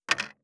591肉切りナイフ,まな板の上,
効果音厨房/台所/レストラン/kitchen食器食材